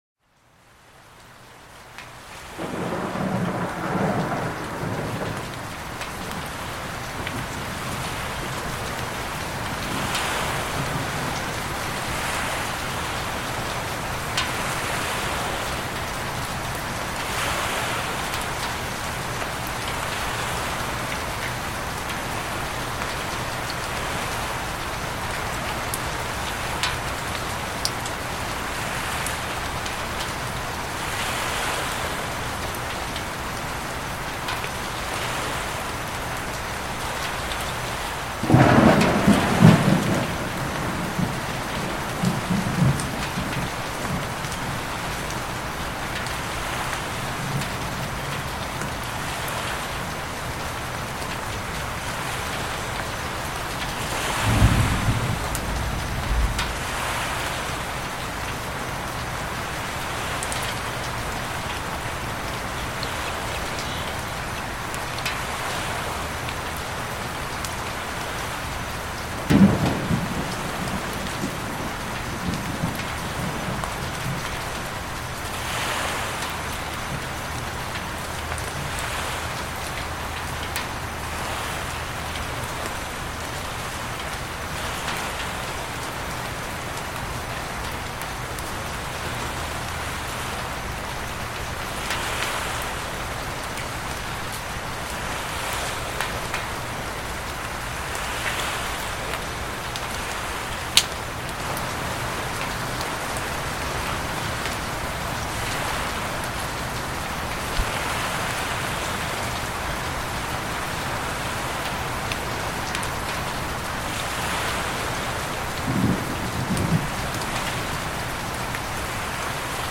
Lluvia Natural para un Sueño Suave y Renovador
Sonido de Lluvia, Lluvia Relajante, Lluvia Suave, Lluvia Nocturna, Descanso Con Lluvia